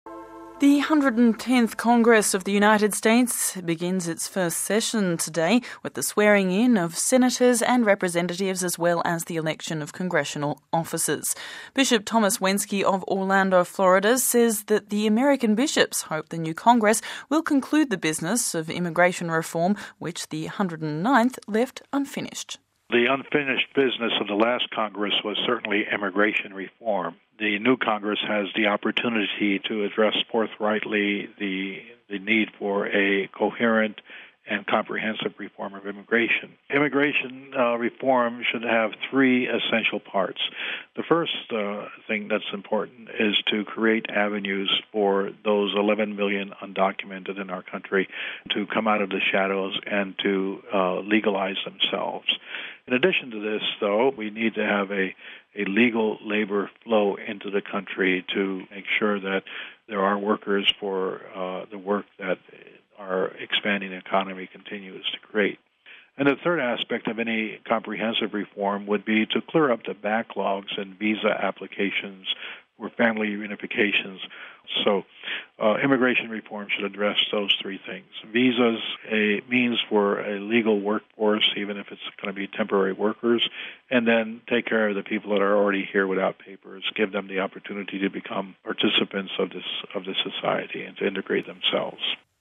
(04 Jan 2007 - RV) The 110th Congress of the United States begins its first session today, with the swearing-in of Senators and representatives, as well as the election of Congressional Officers. We spoke to Bishop Thomas Wenski of Orlando Florida...